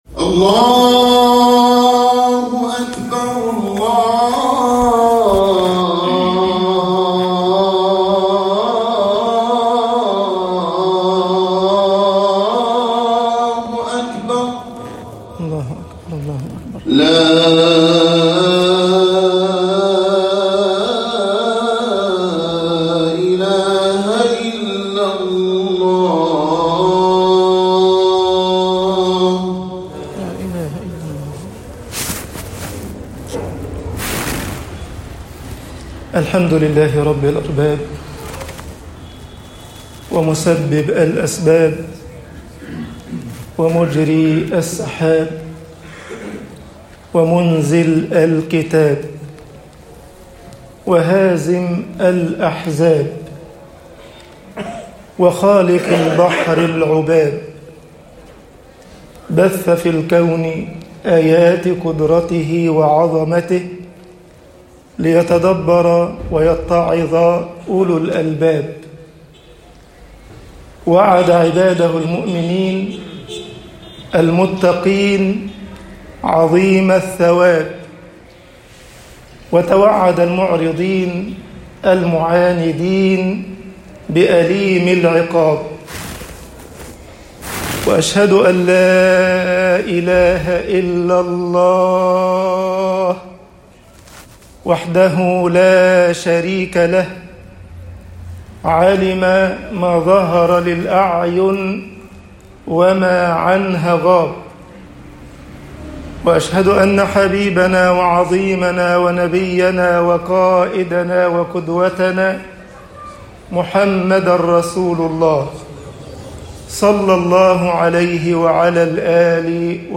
خطب الجمعة - مصر أنْفَعُ الكَلام طباعة البريد الإلكتروني التفاصيل كتب بواسطة